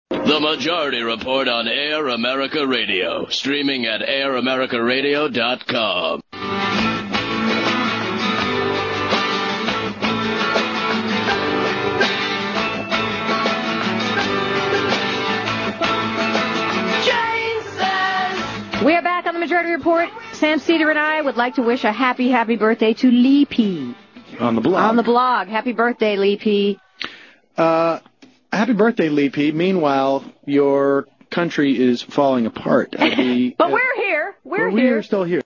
Well, this year when my birthday rolled around on 1-6-2005, Janeane and Sam were really cool and mentioned my name live on the air that night on their show.